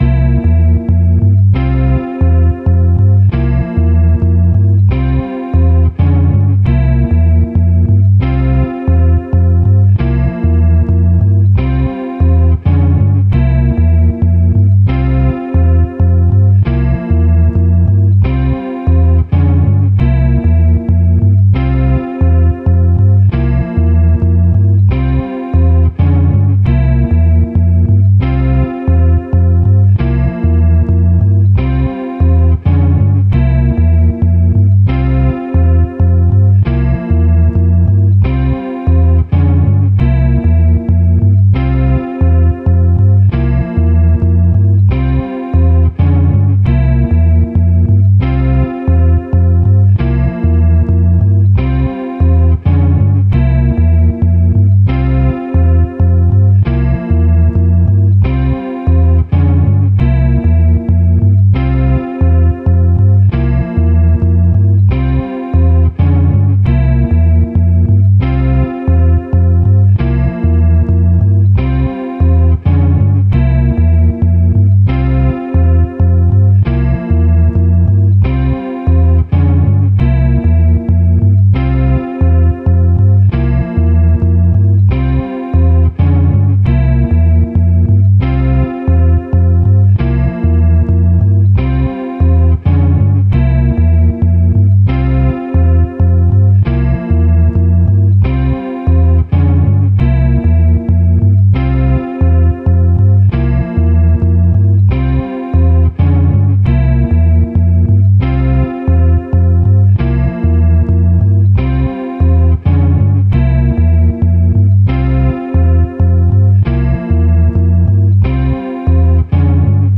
Da wir schon im Anfangsstadium das Intro immer als Latin gespielt hatten, war mein Basisgroove ein Songo.
Dabei spielt man die Cascara mit der rechten Hand auf dem Ridebecken, füllt mit der linken Hand mit der Hihat auf und spielt die Bombonote (2und) auf der Bassdrum. Die Snare wird dann im 3er Takt der Clave auf 4 gespielt.
Ich habe Euch die Grooves alle mal aufgenommen und auf diesem PDF stehen alle Grooves zusammengefasst drauf.